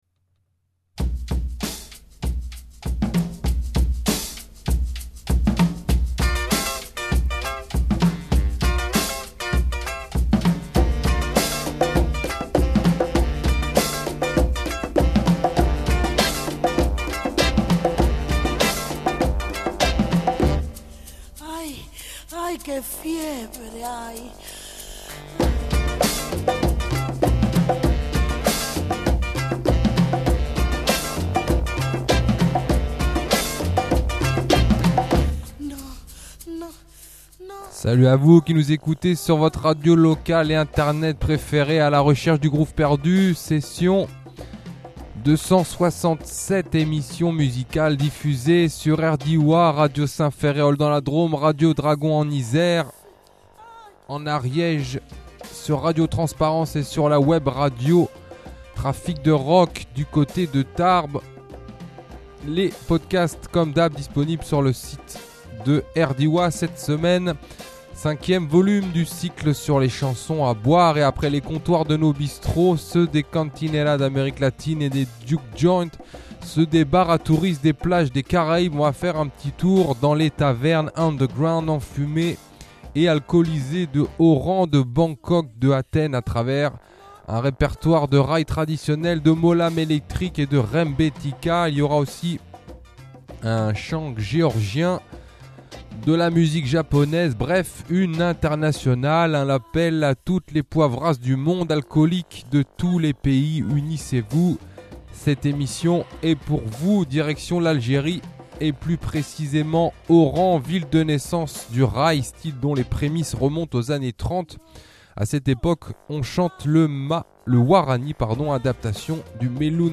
Télécharger en MP3 Cette semaine, 5ème volume du cycle sur les chansons à boire et après les comptoirs de nos bistrots, ceux des cantinera d’amérique latine et des juke joint, ceux des bars à touristes des plages des caraibes, on va faire un petit tour dans les tavernes underground, enfumées et alcoolisées de Oran, de Bangkok, de Athènes à travers un répertoire de Rai traditionnel , de Molam electrique et de rembetika. Il y aura aussi un chant géorgien, de la musique japonaise, bref, une internationale, un appel à toutes les poivrasses du monde.